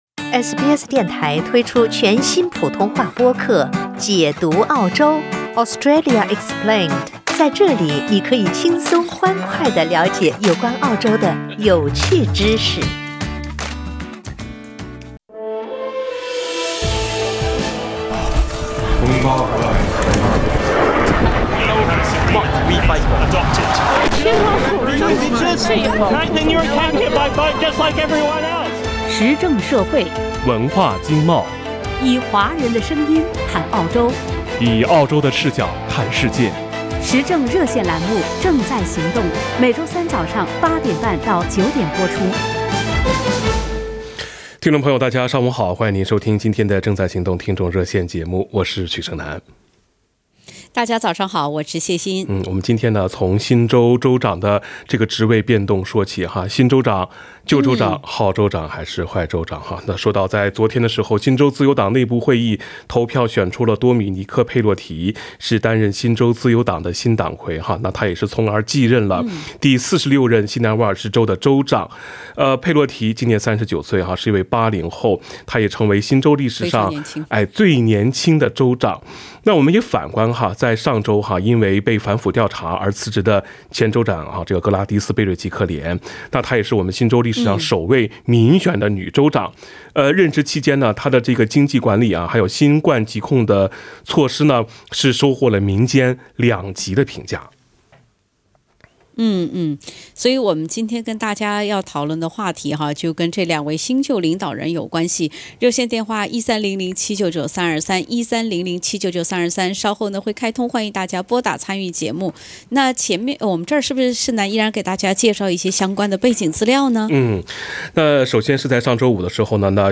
参与《正在行动》热线的许多维州听众都认为，相比他们本地疫情的恶化和不断反复，新州疫情控制相对较好得益于贝瑞吉克莲新州政府的领导有方。